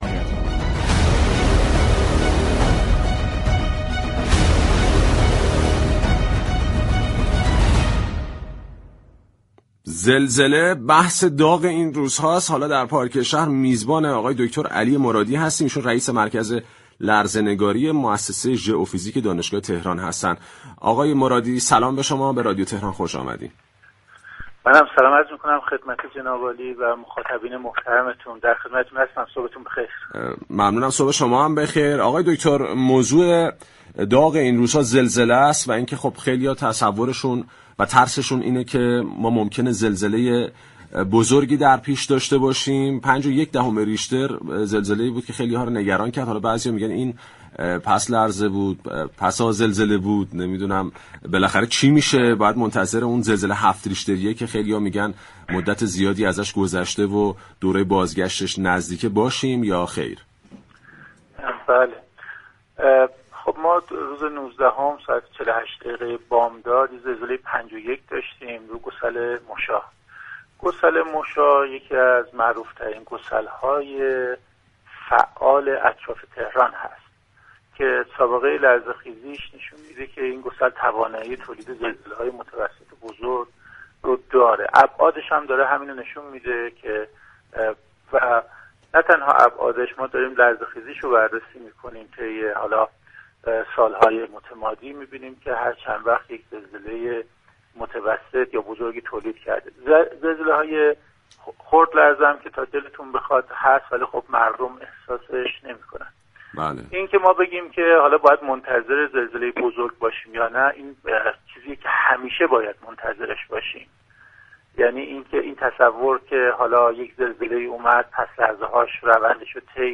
در گفتگو با برنامه پارك شهر 20 اردیبهشت ماه اعلام كرد كه تهرانی ها همیشه باید منتظر زلزله 7 ریشتری باشند.